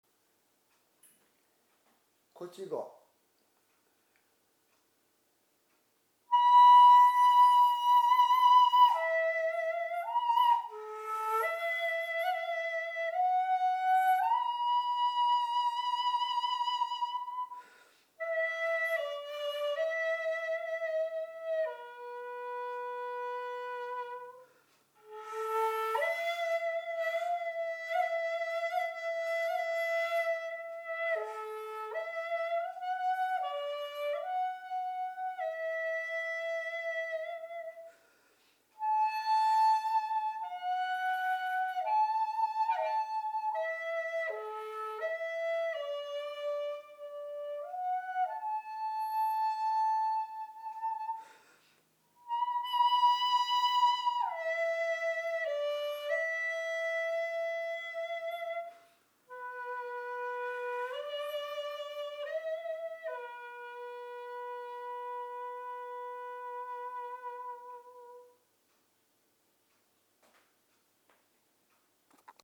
そうです。一節切の音色です。